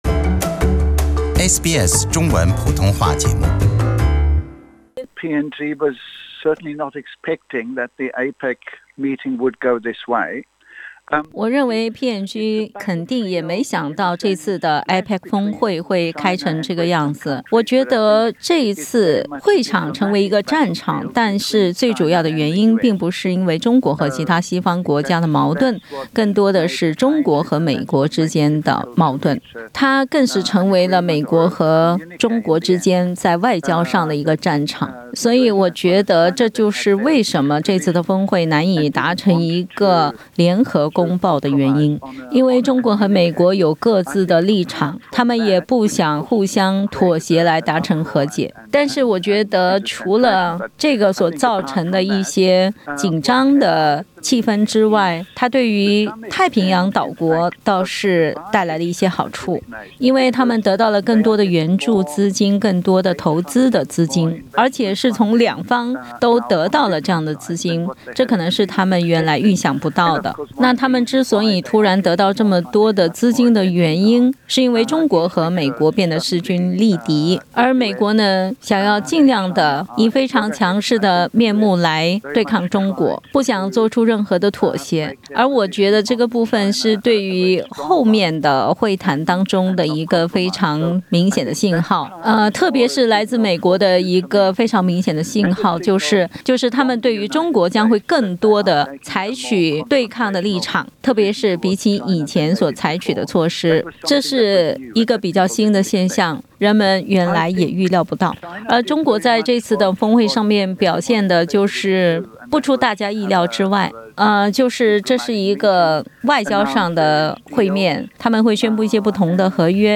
（本节目为嘉宾观点，不代表本台立场。）